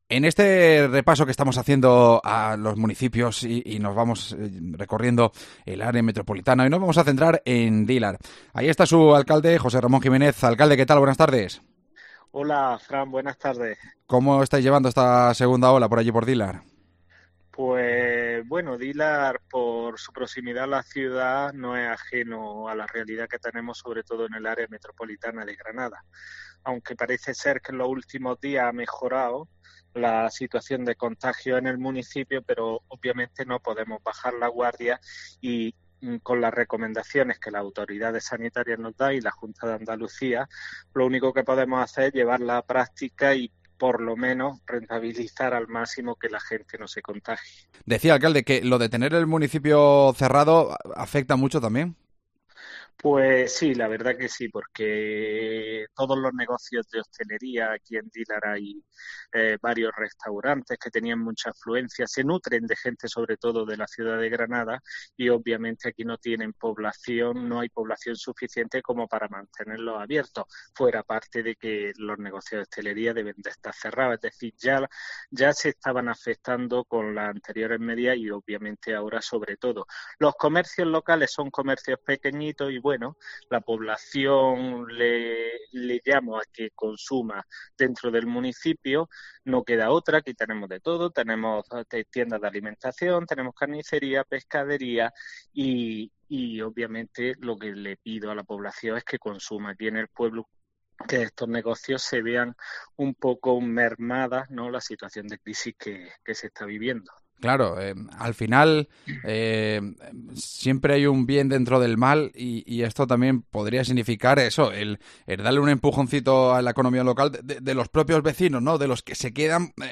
AUDIO: Hablamos con su alcalde José Ramón Jiménez